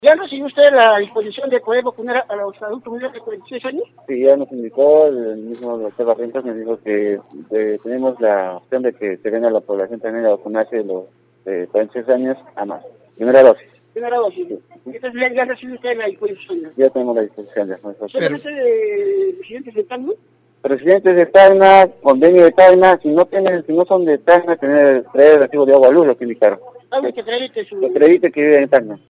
Radio Uno consultó a personal de salud en la I.E. E. Coronel Bolognesi sobre la disposición.
PERSONAL-DE-SALUD.mp3